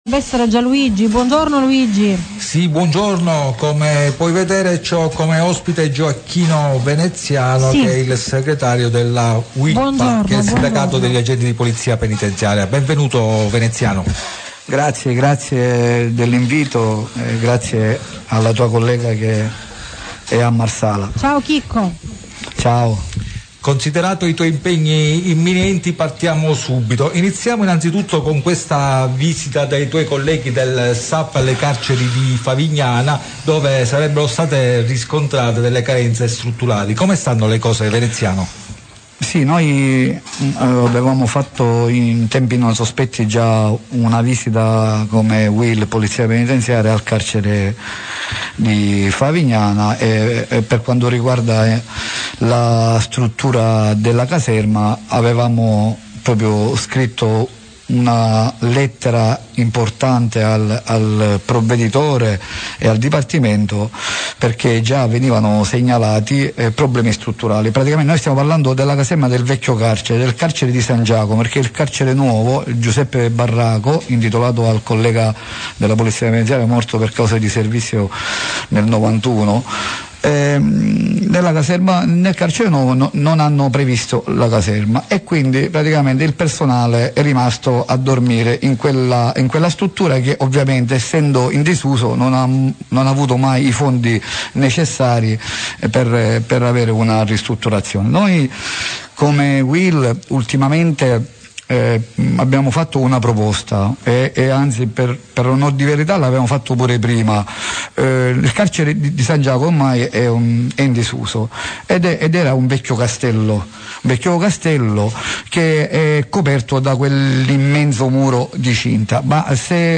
DIRETTA DI RMC101 PROGRAMMA IL VOLATORE